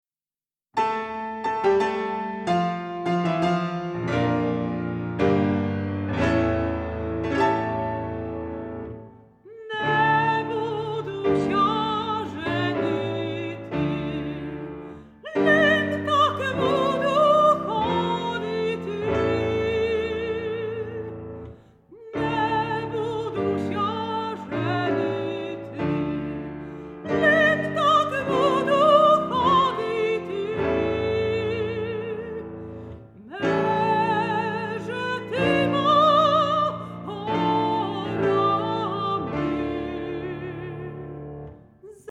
mezzosopran
fortepian